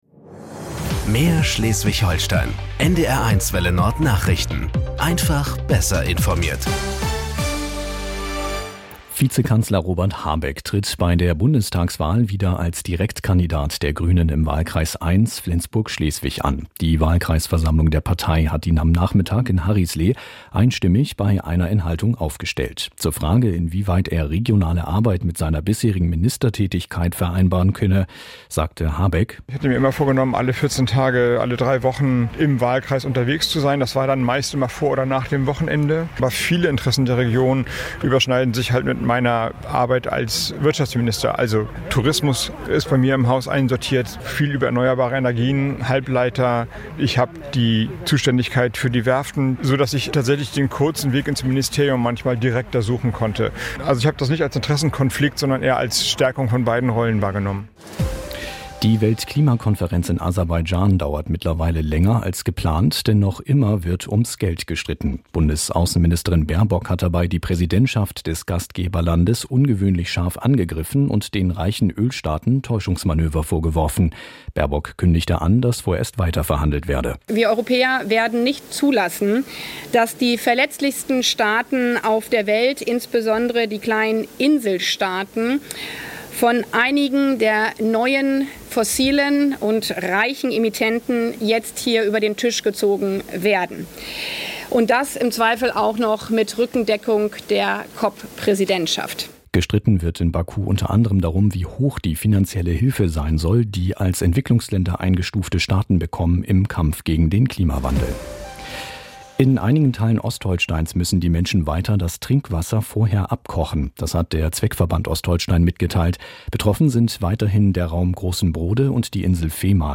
Nachrichten 20:00 Uhr.